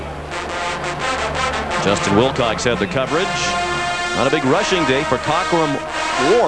The Trombone section has several "section cheers" that we perform during games.